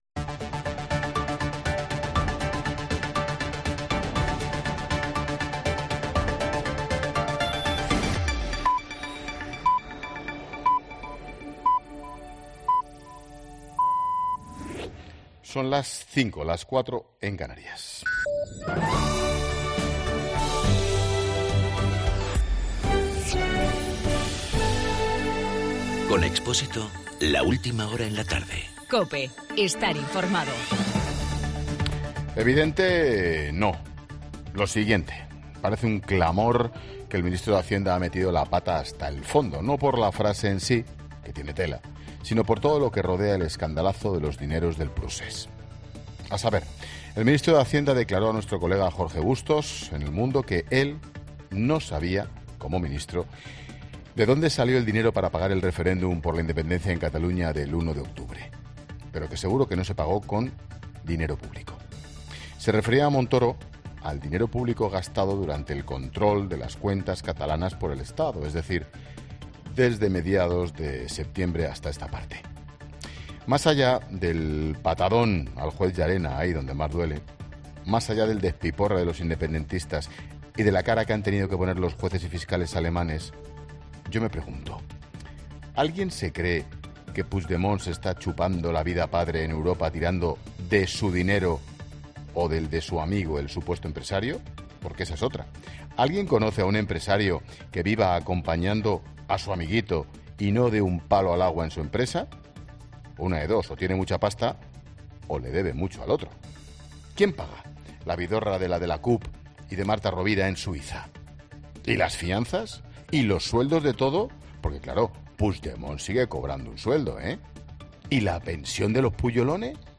Monólogo de Expósito
Comentario de Ángel Expósito a las 17 horas sobre la polémica de Cristóbal Montoro.